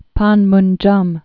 (pänmnjŭm)